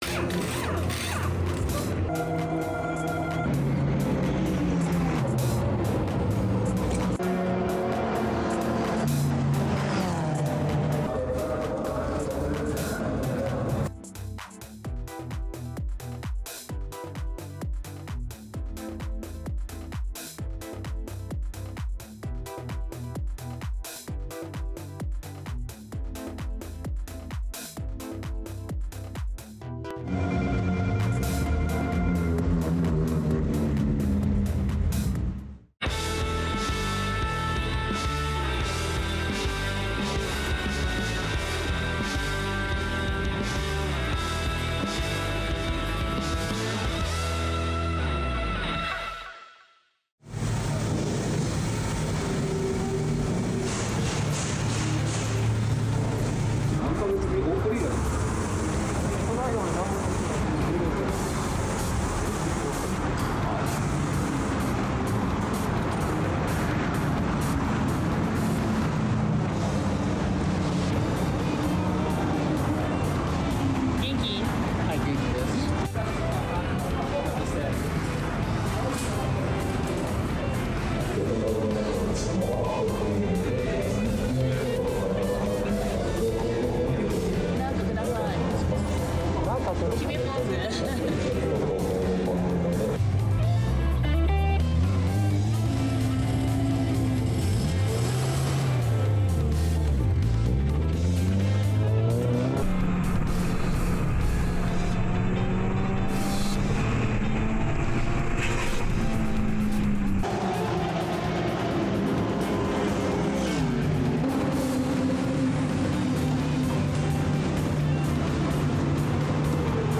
富士スピードウェイに全57台が集結し、最終戦にふさわしい熱気に包まれた一日。浅野レーシングサービス18号車 WedsSport GR86は、セットアップの方向性を探りながら各ドライバーが丁寧に走行を重ね、予選ではクラス7位を獲得しました。